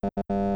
sfx_keypadDeniedd.wav